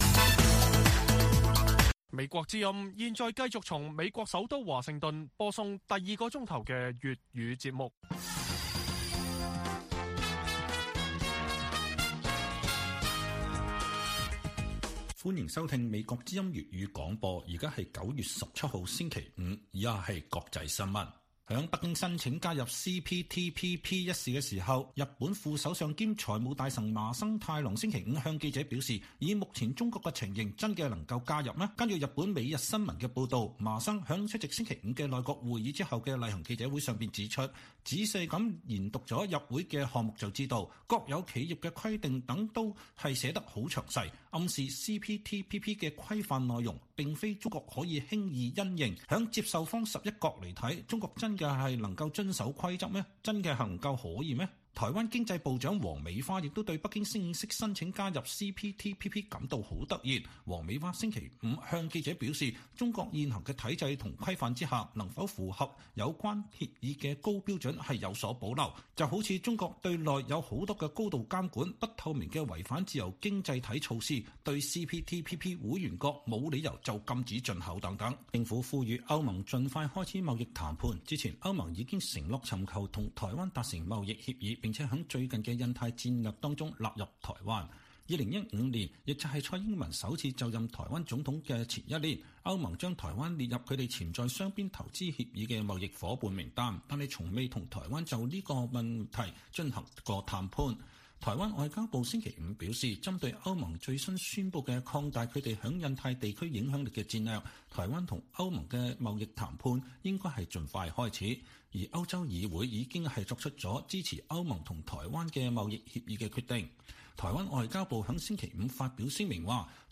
粵語新聞 晚上10-11點: 北京突然申請加入CPTPP 是否準備完畢受質疑